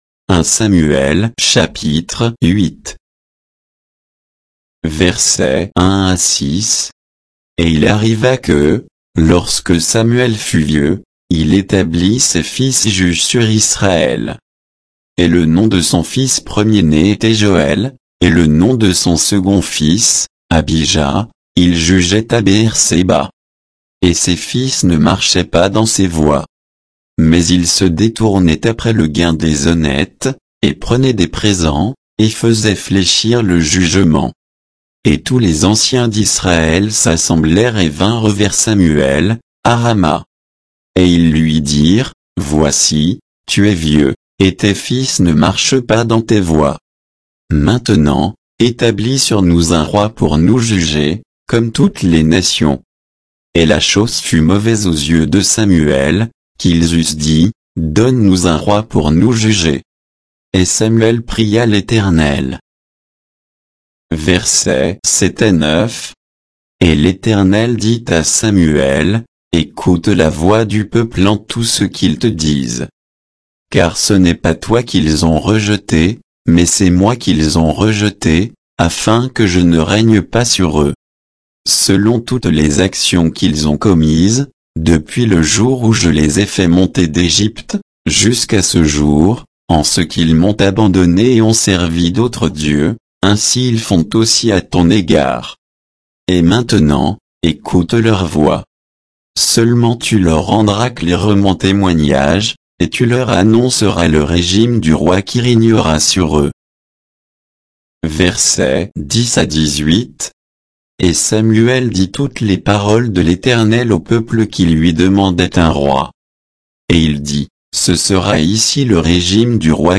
Bible_1_Samuel_8_(avec_notes_et_indications_de_versets).mp3